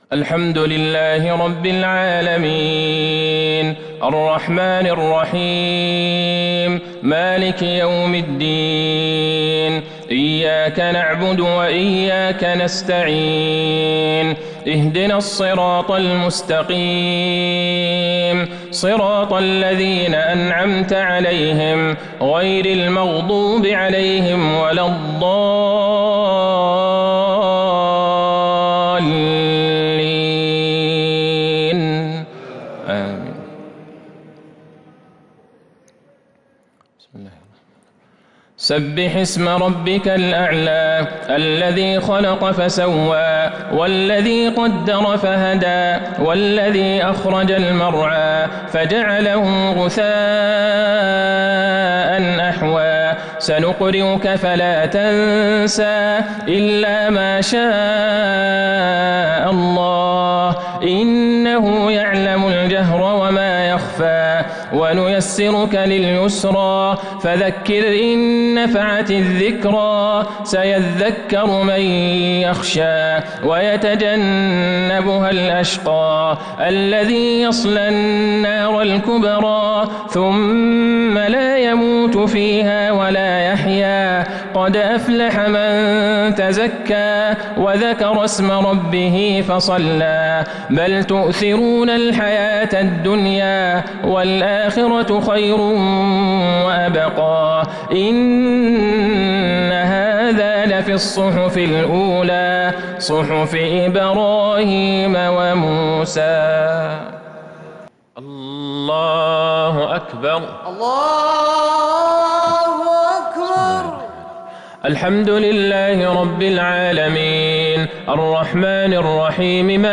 صلاة الجمعة 8-2-1442 هـ سورتي الأعلى والغاشية | Jumu'ah prayer Surah Al-A'laa and Al-Ghaashiya 25/9/2020 > 1442 🕌 > الفروض - تلاوات الحرمين